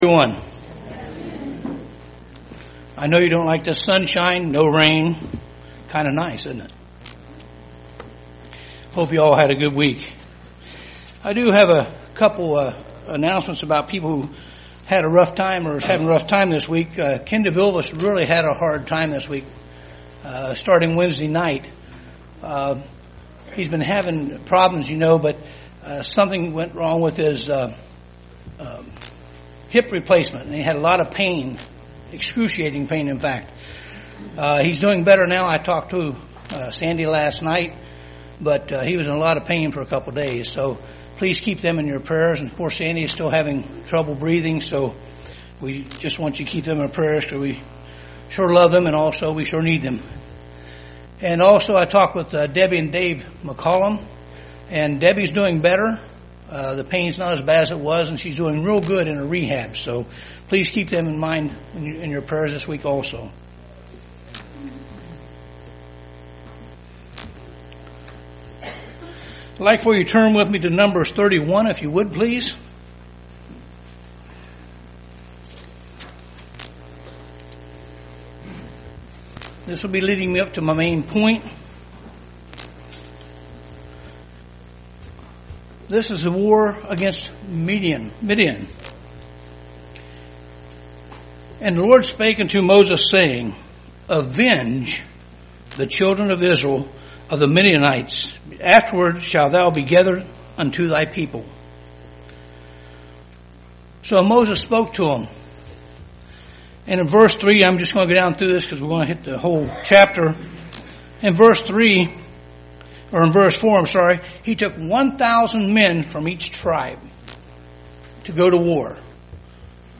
Print What that you do not find yourself trapped in a Laodicean Era in your life UCG Sermon Studying the bible?
Given in Dayton, OH